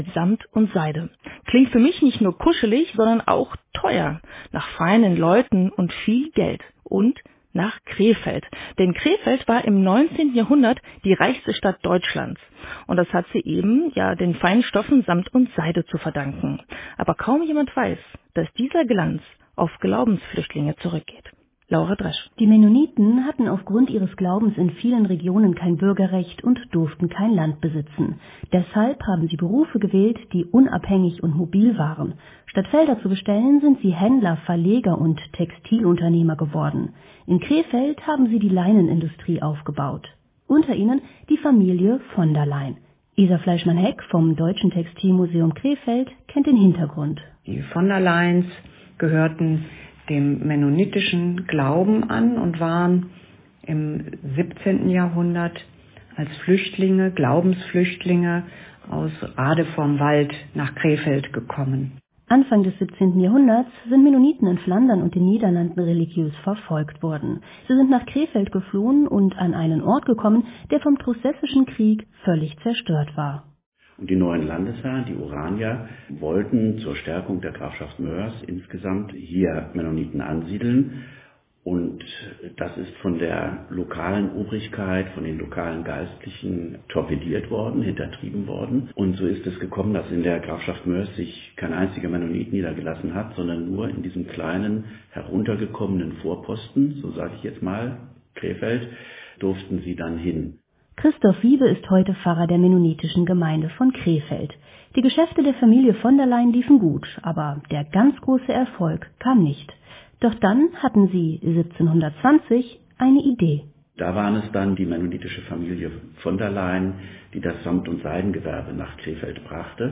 Der entsprechende Mitschnitt der Sendung kann hier angehört werden: